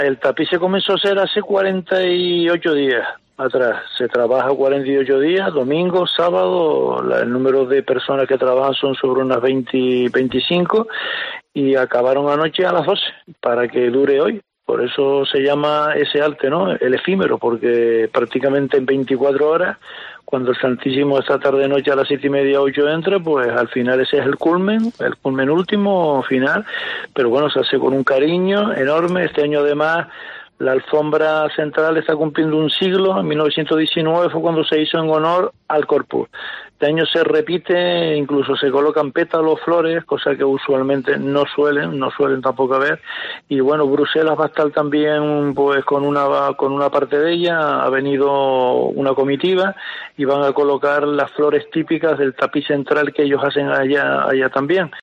Francisco Linares, alcalde de La Orotava